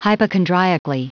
Prononciation du mot hypochondriacally en anglais (fichier audio)
hypochondriacally.wav